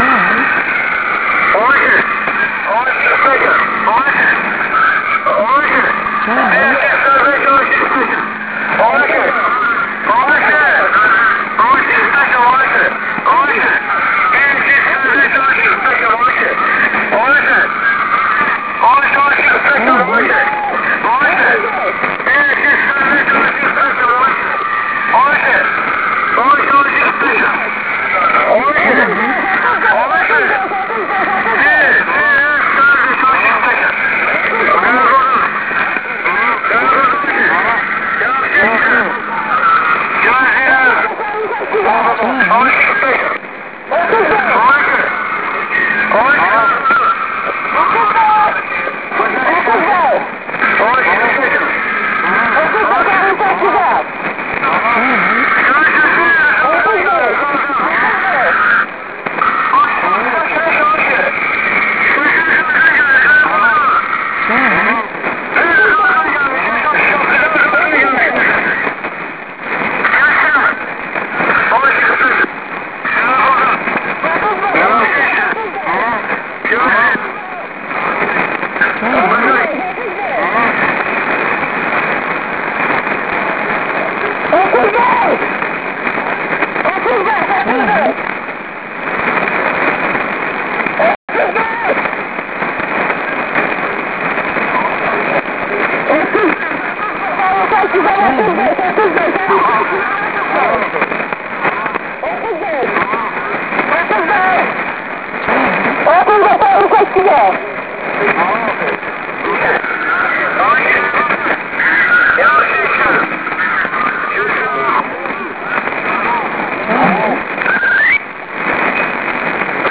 5520 кГц, неизвестный сигнал